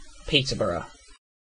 Peterborough (/ˈptərbərə, -bʌrə/
En-uk-Peterborough.ogg.mp3